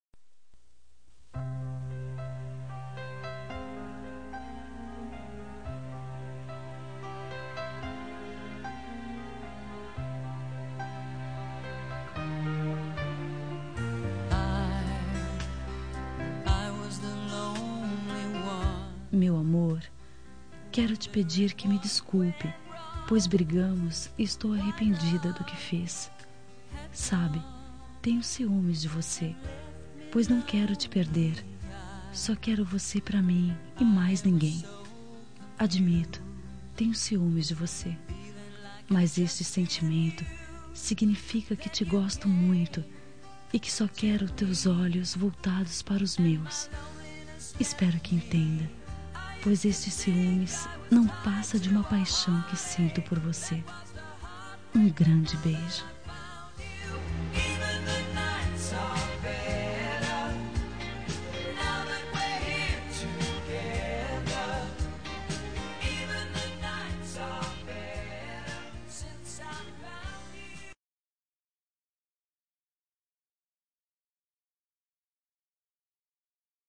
Telemensagem de Desculpas – Voz Feminina – Cód: 363 – Ciúmes